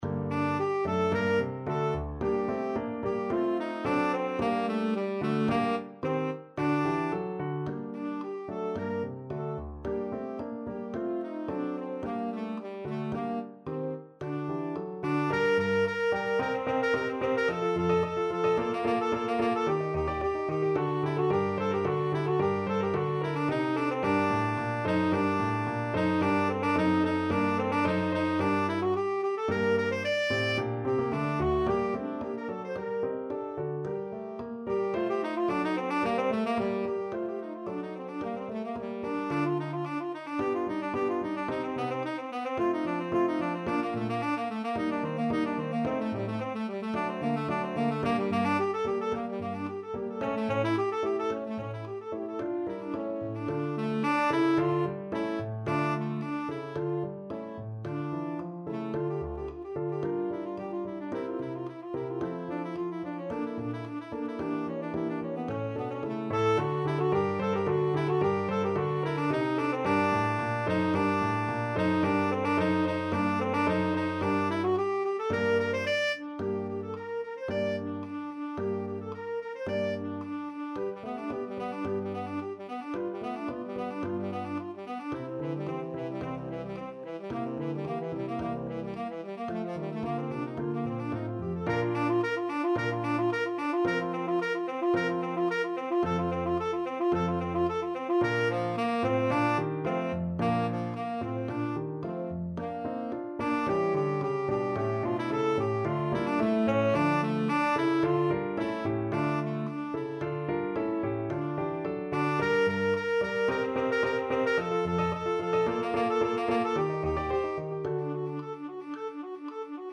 2/4 (View more 2/4 Music)
= 110 Presto (View more music marked Presto)
Tenor Saxophone  (View more Advanced Tenor Saxophone Music)
Classical (View more Classical Tenor Saxophone Music)